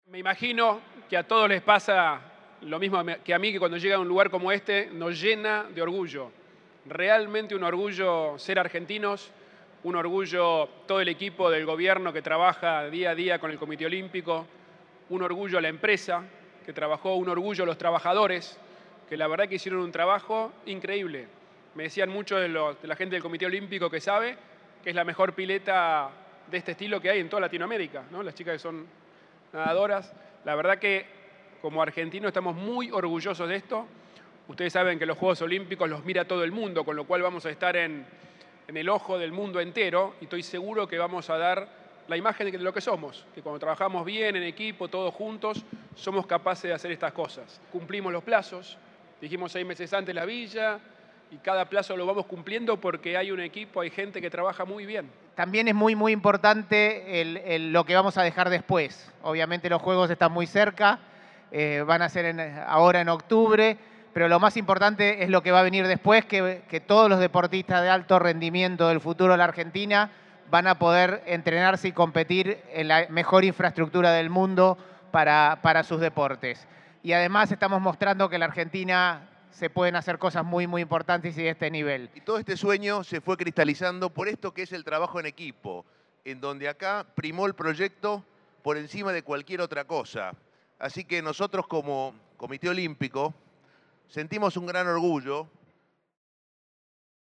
Macri y Rodríguez Larreta, en un asado con los obreros que construyeron la pileta olímpica